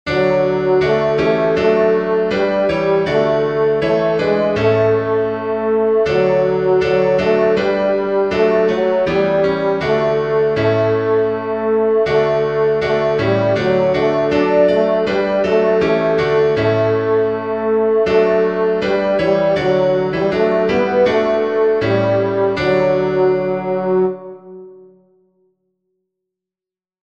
Tenor
duke_street_i_know_that_my_redeemer_lives-tenor.mp3